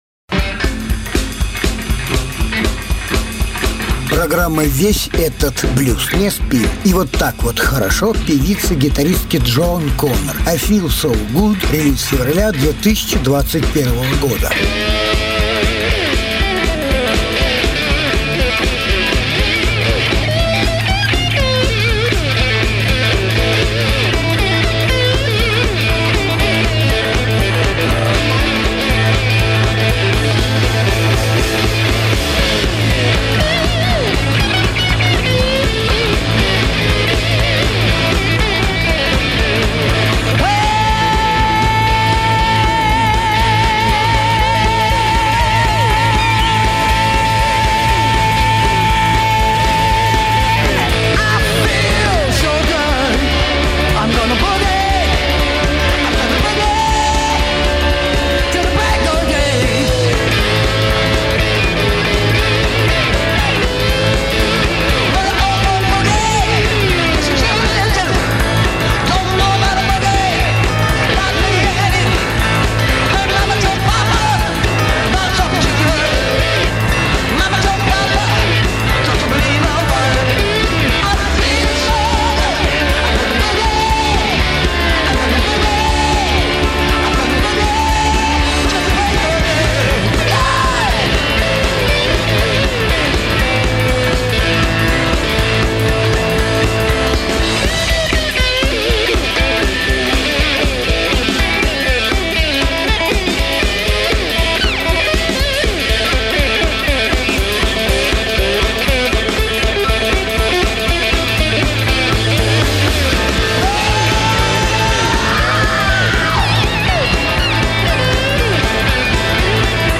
гитаристка